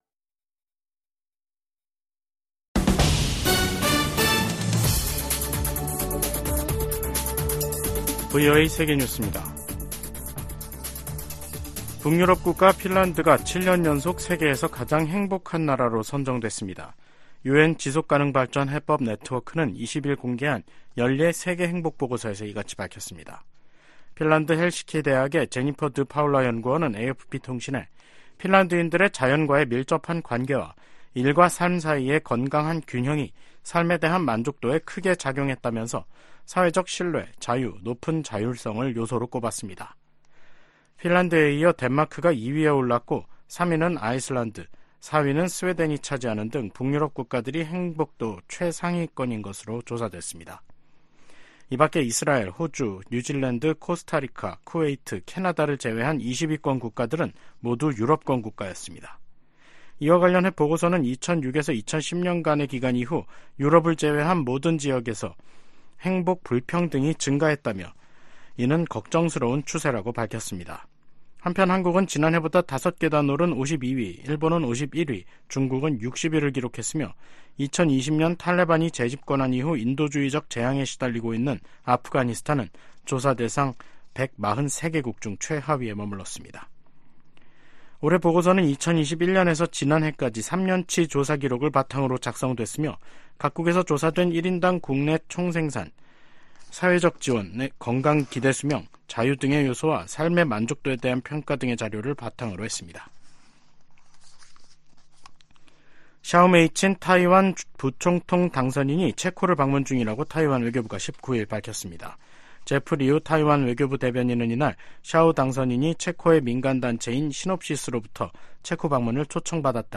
VOA 한국어 간판 뉴스 프로그램 '뉴스 투데이', 2024년 3월 20일 3부 방송입니다. 북한이 신형 중장거리 극초음속 미사일에 사용할 다단계 고체연료 엔진 시험에 성공했다고 관영 매체들이 보도했습니다.